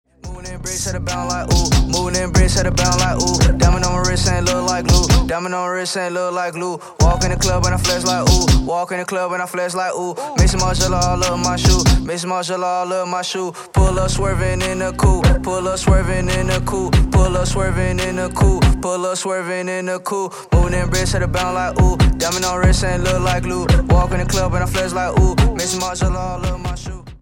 • Качество: 320, Stereo
Хип-хоп
спокойные
качающие
Bass